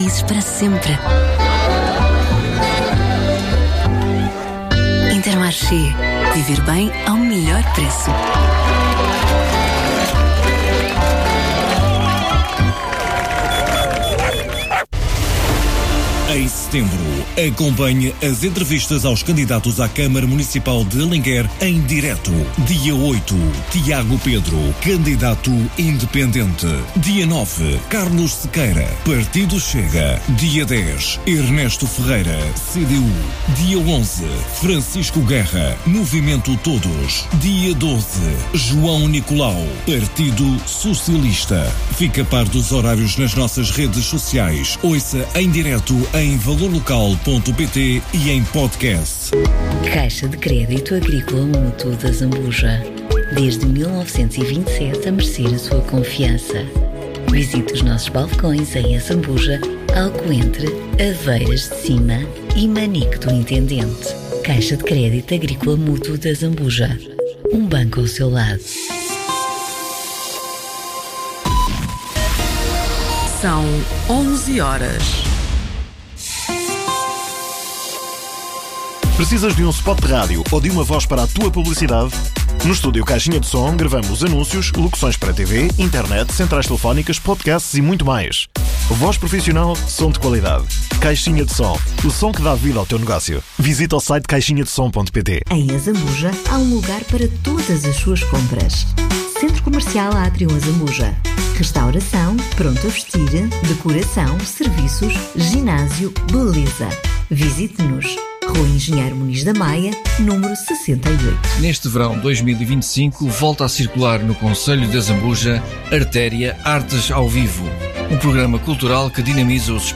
Autárquicas 2025 - Alenquer - Entrevista